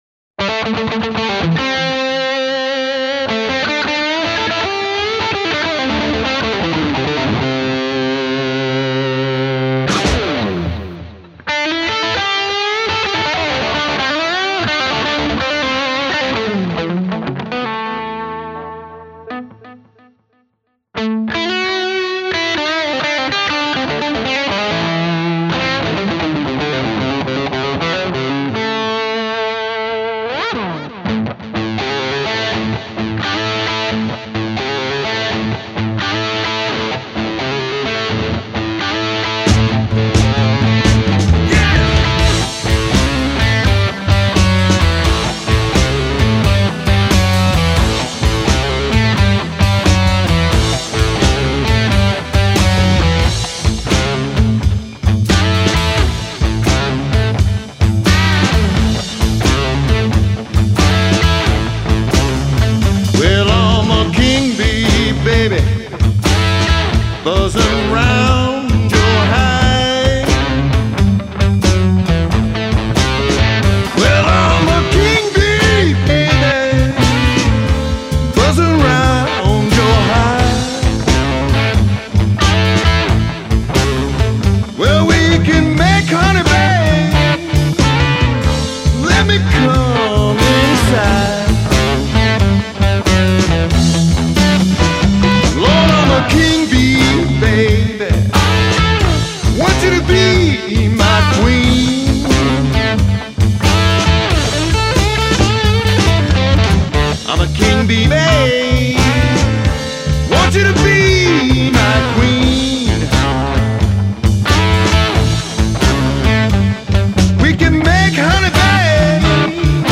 electric contemporary blues